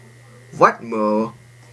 Pronunciation of Whetmer /ˈʋetmɘː/ in Whetmer